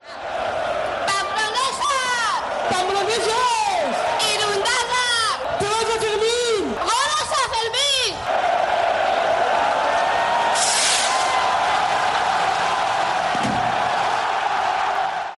Sonido del chupinazo de los Sanfermines 2018